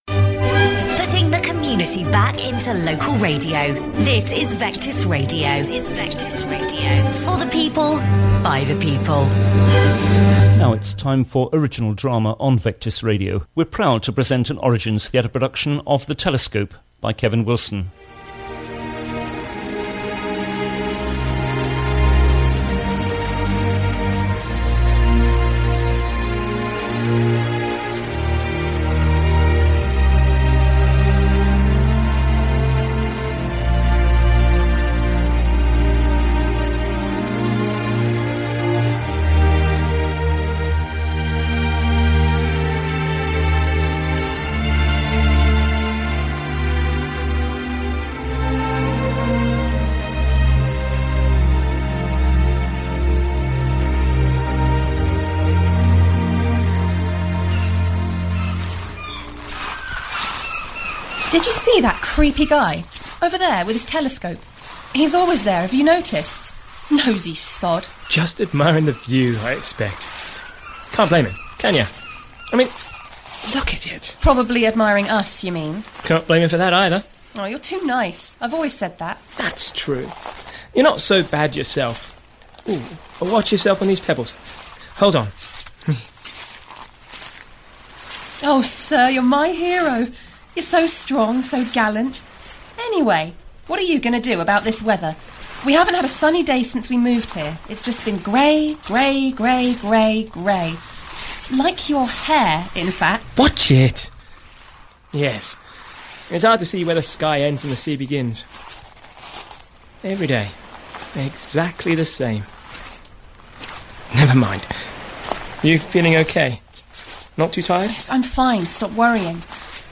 Original Drama - The Telescope